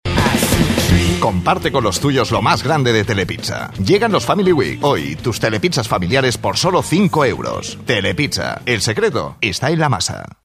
Mesa Soundcraft Altavoces Yamaha Microfono Sennheiser MD 441 U Software Adobe Audition
Sprechprobe: Industrie (Muttersprache):
My accent is spanish neutral She has a friendly, expressive, seductive and professional voice.